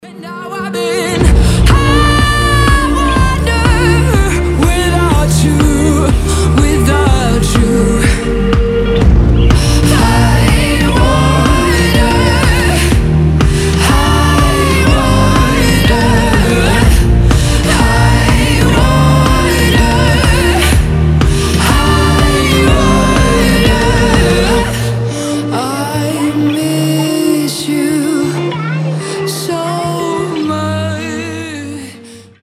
грустные
сильные
indie pop
alternative
печальные